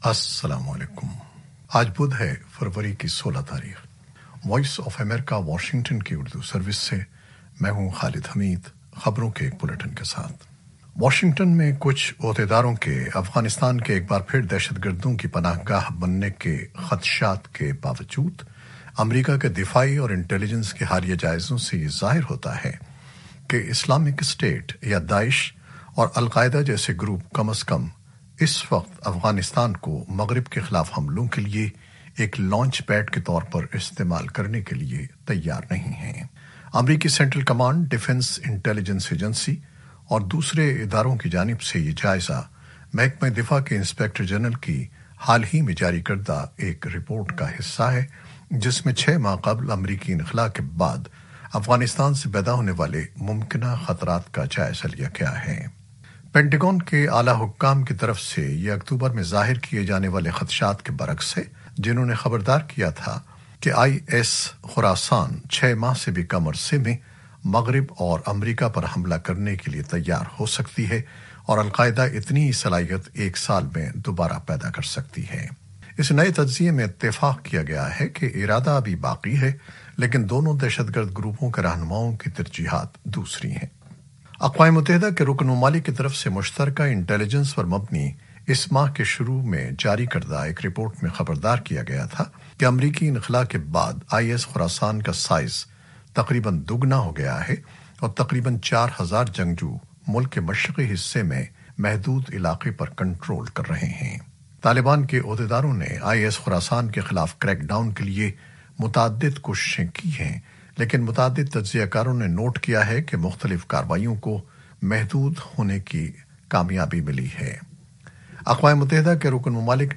نیوز بلیٹن 2021-16-02